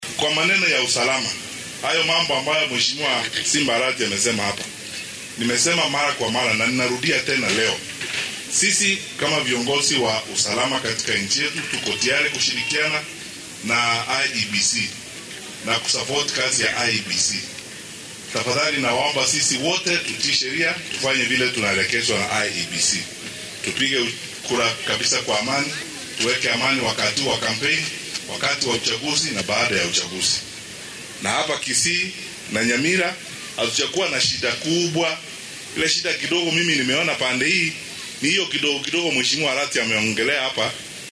Wasiirka wasaaradda arrimaha gudaha ee dalka Dr.Fred Matiangi ayaa xilli uu ku sugnaa Kisii dadweynaha ugu baaqay inay si nabad ah uga qayb qaataan doorashada